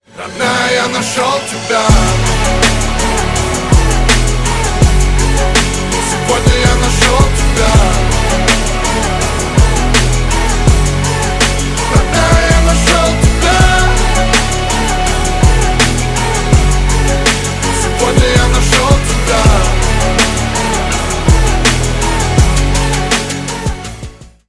Романтические рингтоны